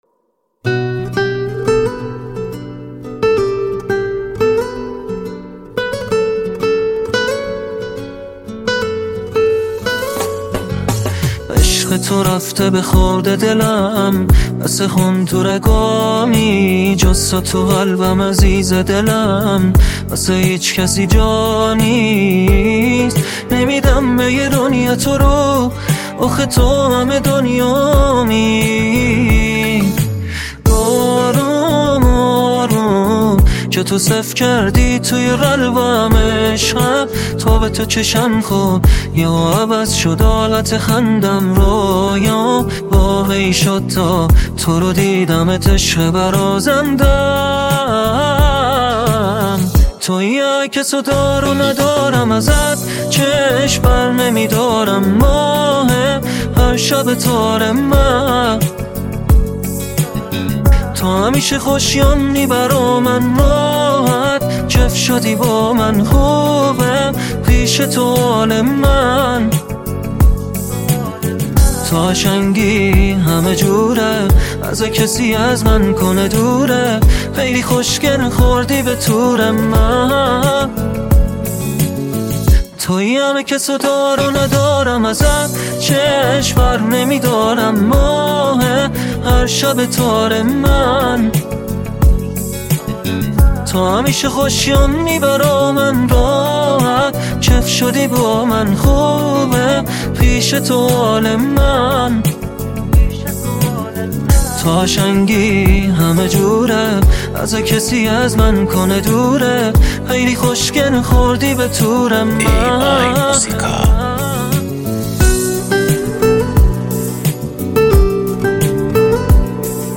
ساخته شده با هوش مصنوعی ☼